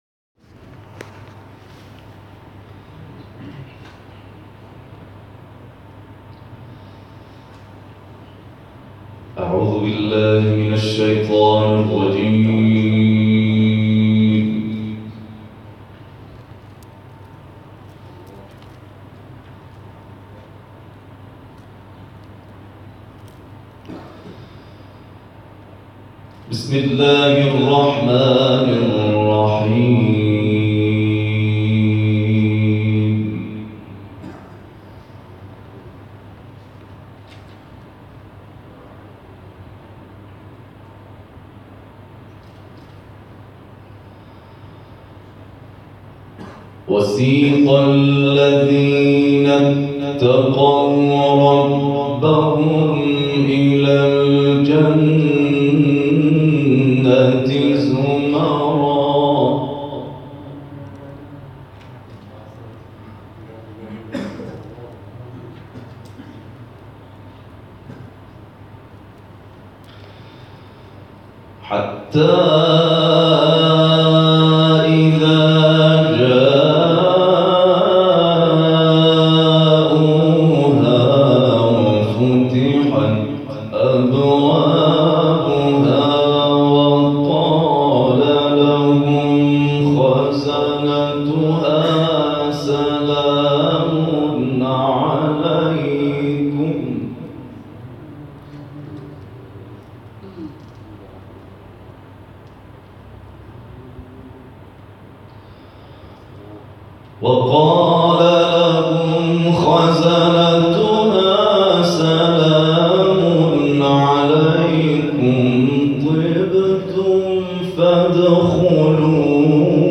محفل انس با قرآن در اداره زندان‌های استان کرمانشاه